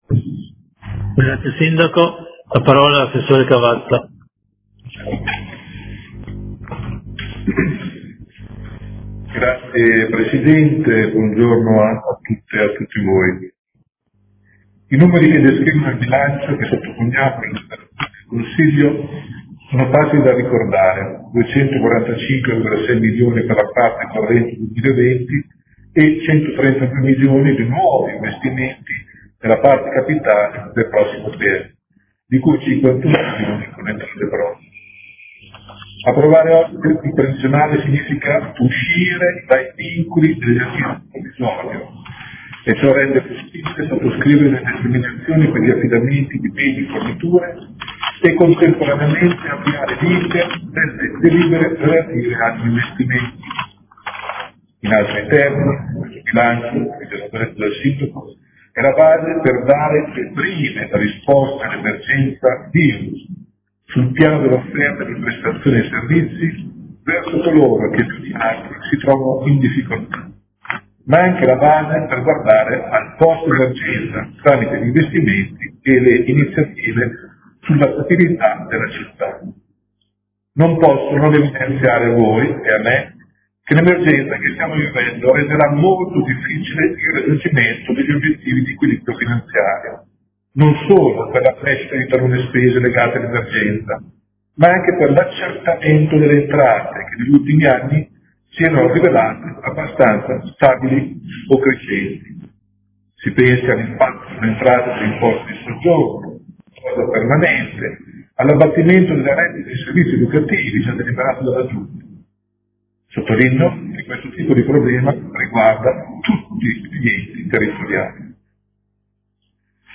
Dibattito su proposte di deliberazione, emendamenti e ordini del giorno sul bilancio